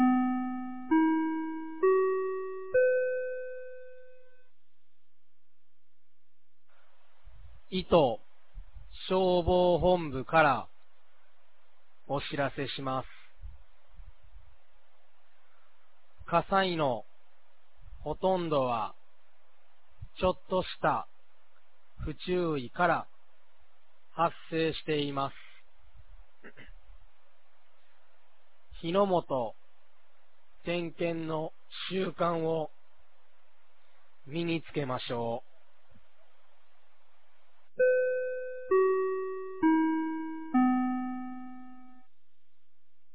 2024年06月03日 10時00分に、九度山町より全地区へ放送がありました。
放送音声